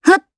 Gremory-Vox_Attack1_jp.wav